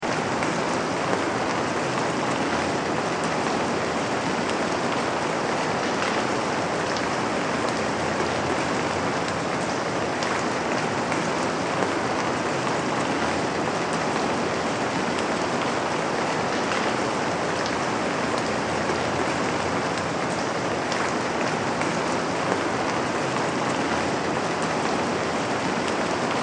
دانلود آهنگ باران 8 از افکت صوتی طبیعت و محیط
جلوه های صوتی
دانلود صدای باران 8 از ساعد نیوز با لینک مستقیم و کیفیت بالا